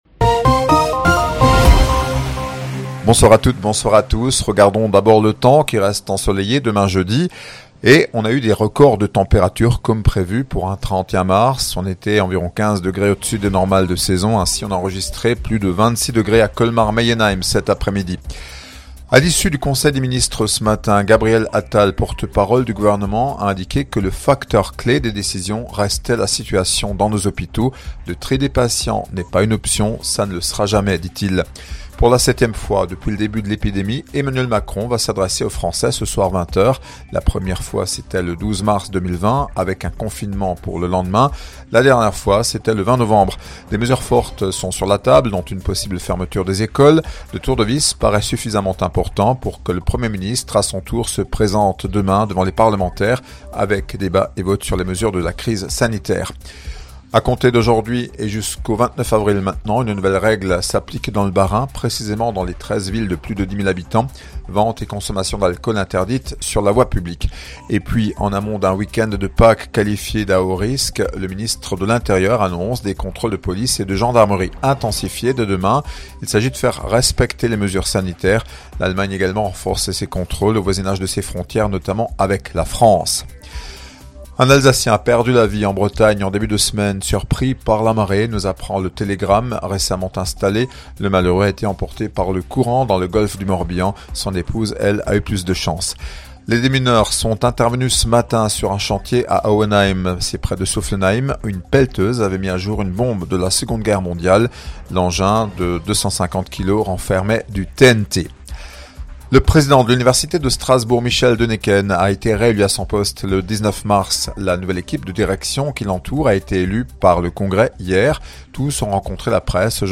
Journal 18h - mercredi 31 mars